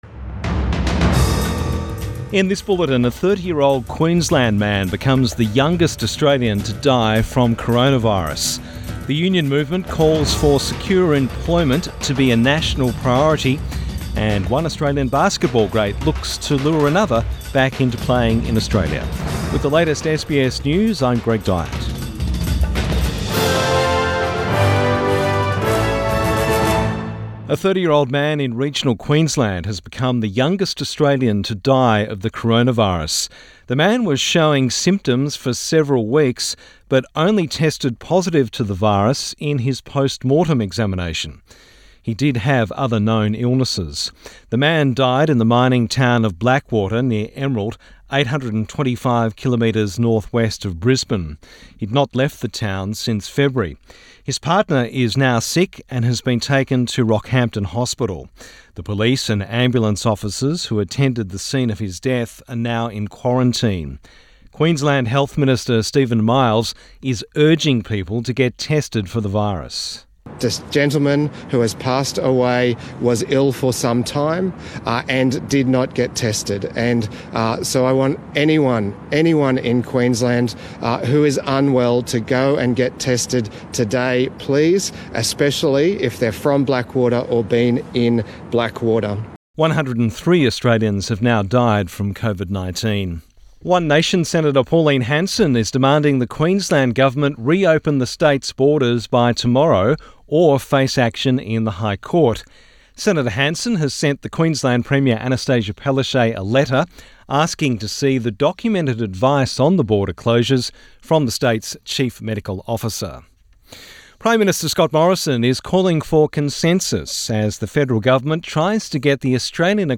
Midday bulletin 27 May 2020